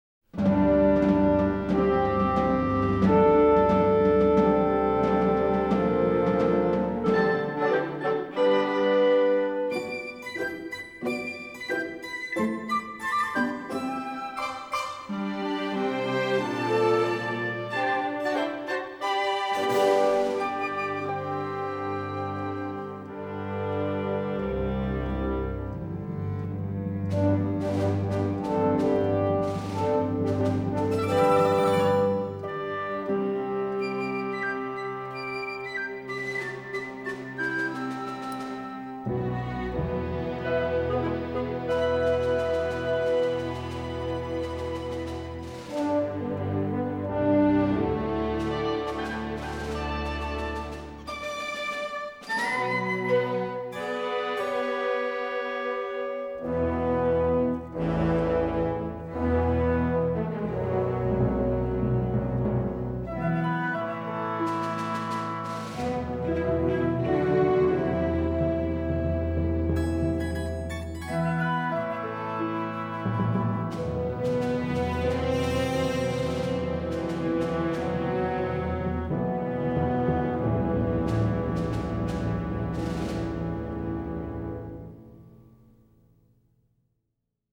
На этой странице вы найдете саундтрек к мультфильму \